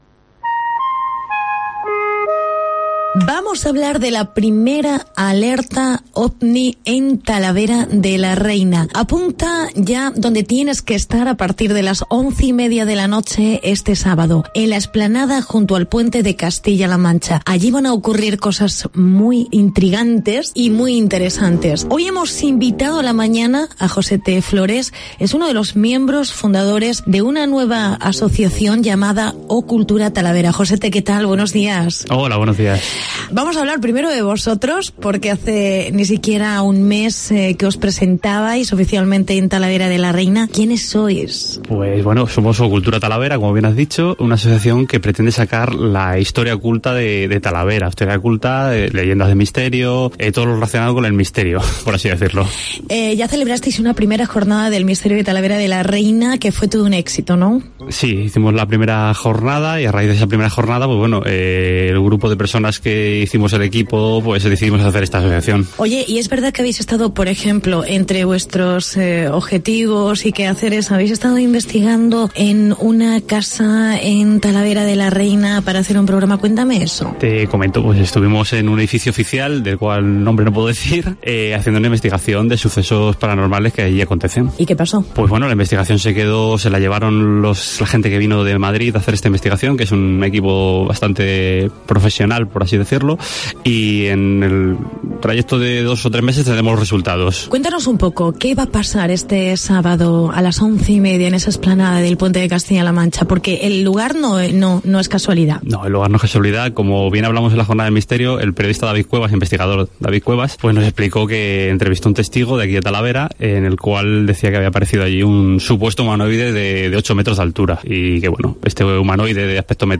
I Alerta OVNI en Talavera. Entrevista